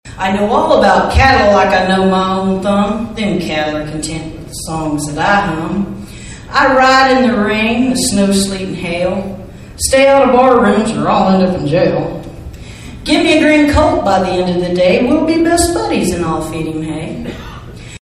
MANHATTAN — The Flint Hills Discovery Center was akin to a wild prairie Friday night for the finals of the Kansas Cowboy Poetry Contest.